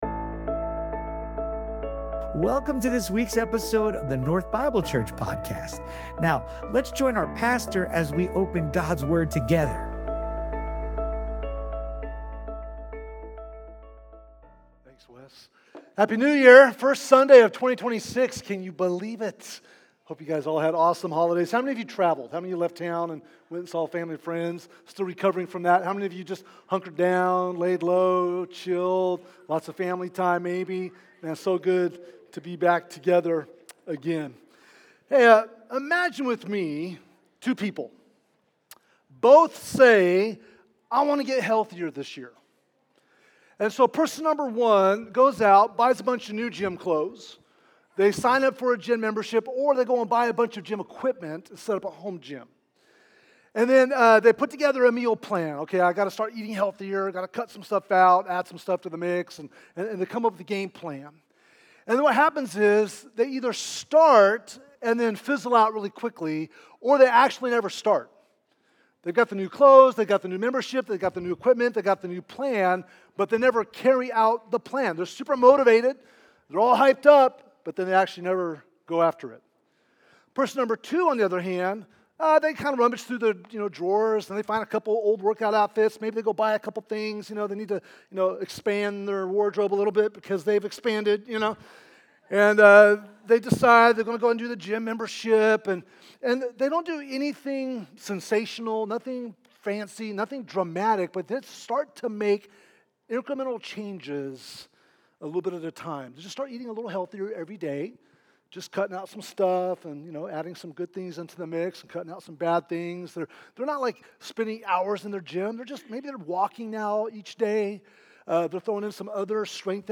These women share their journeys from the past year, discussing their personal and professional wins, and emphasize themes such as perseverance, purpose, and transformation.